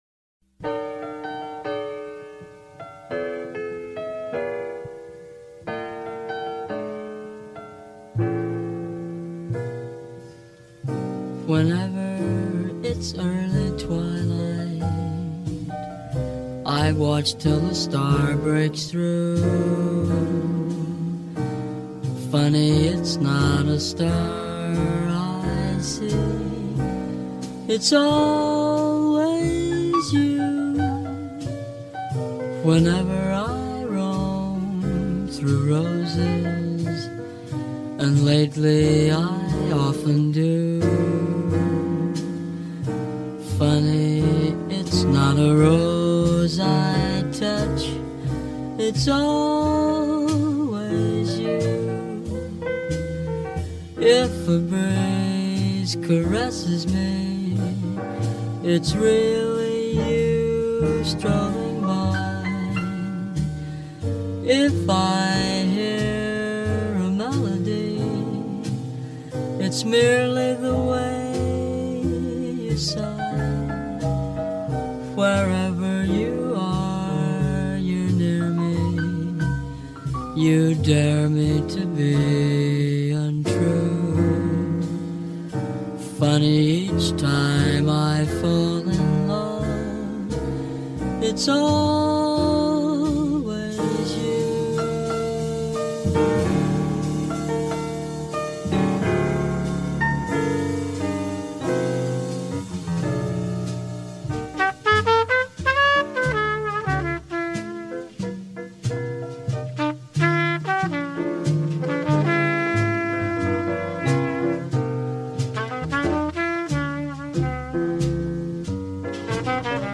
Cool Jazz